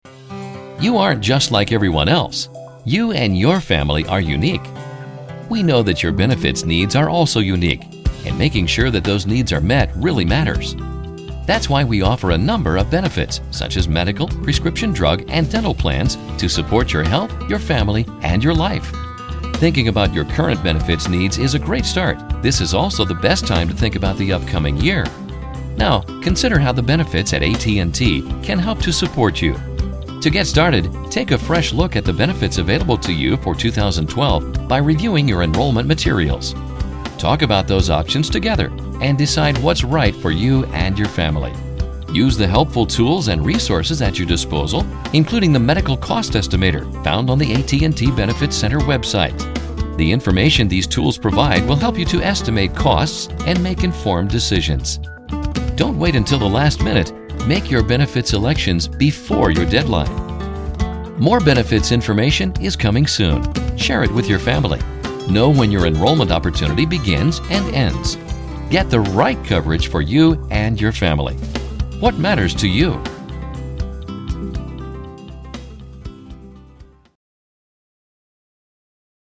I can offer a warm, deep, personable voice over for narrations, training videos and presentations.
Sprechprobe: Industrie (Muttersprache):